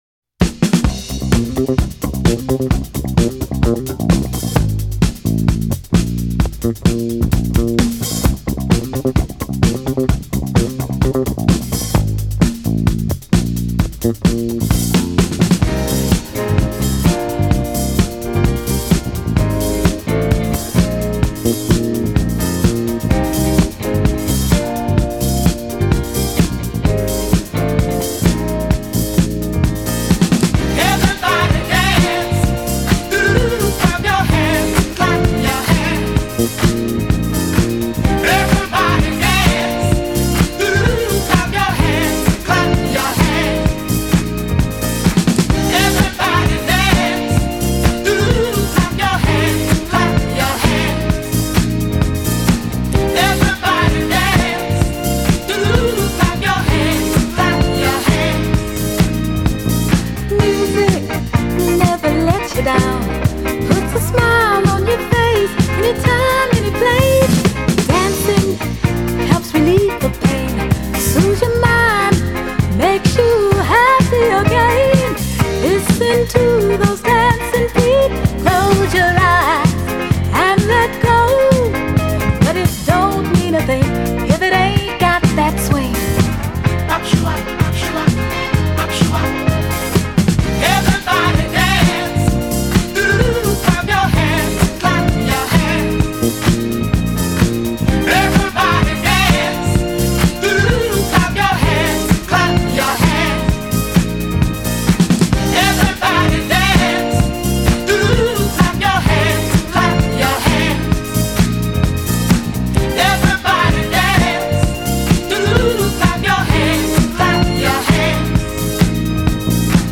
Filed under bangers, disco